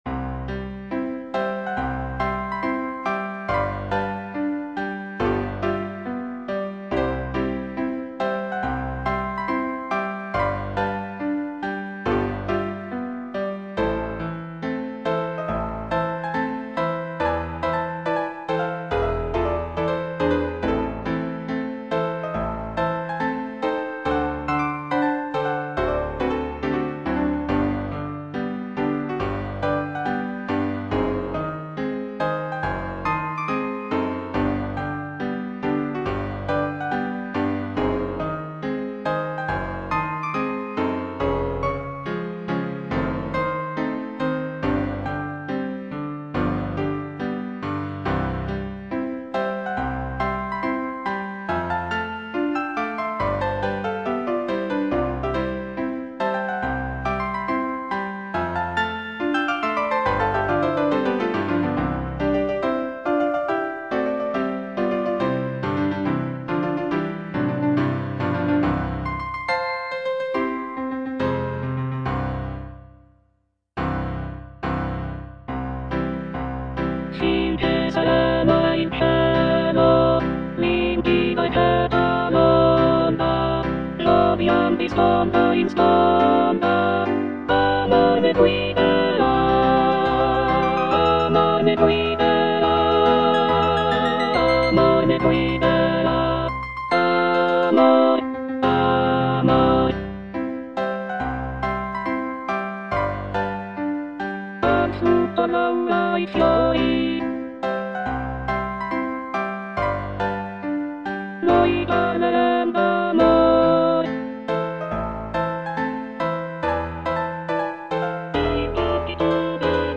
G. ROSSINI - LA PASSEGGIATA Alto (Emphasised voice and other voices) Ads stop: auto-stop